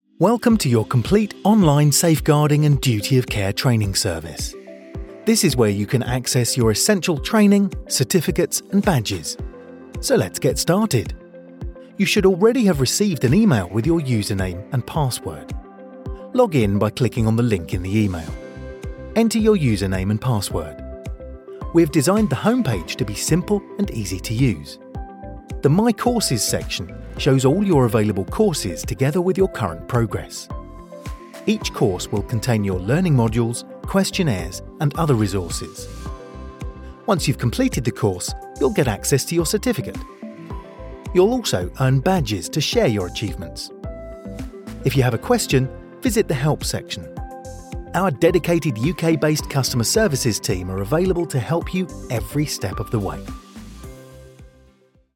une voix de baryton britannique chaleureuse et polyvalente
Apprentissage en ligne
Studio indépendant construit par des professionnels, avec cabine isolée, ce qui signifie que je peux être disponible pour enregistrer 24h/24 et 7j/7, même si mon voisin utilise le souffleur de feuilles !